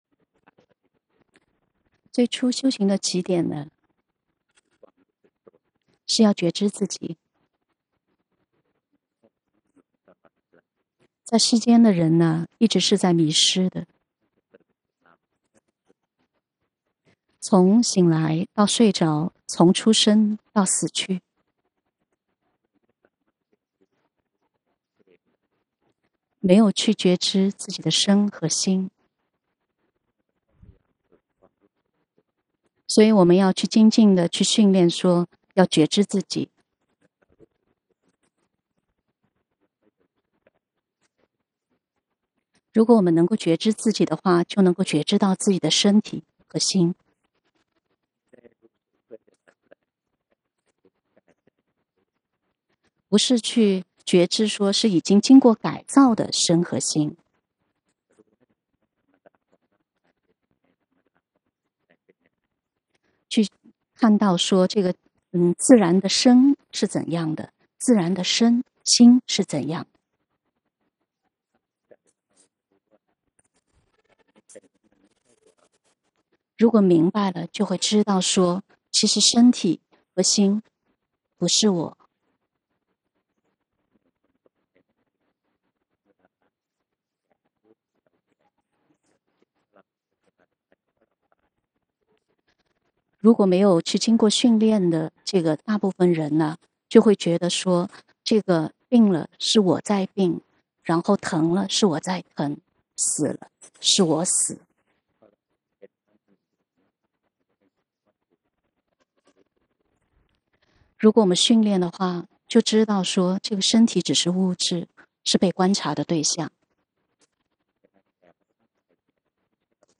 法談摘錄
2023年09月06日｜泰國解脫園寺
完整開示｜音頻